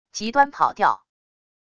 极端跑调wav音频